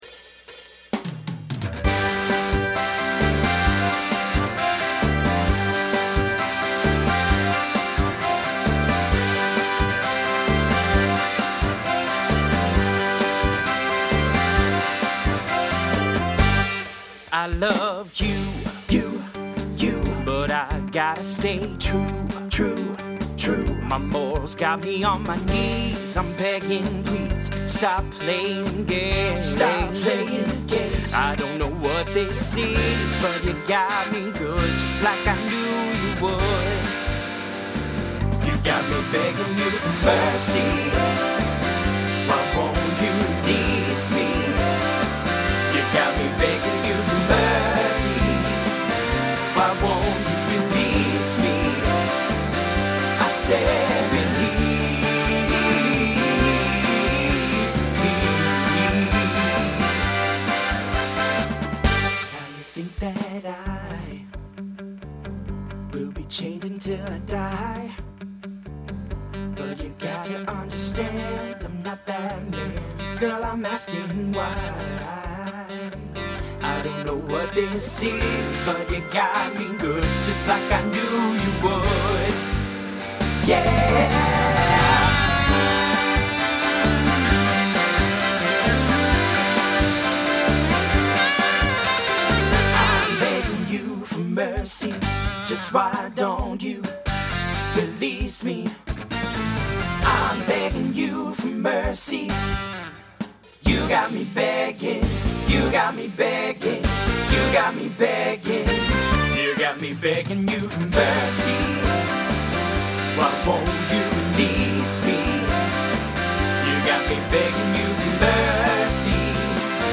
Voicing TTB Instrumental combo Genre Pop/Dance , Rock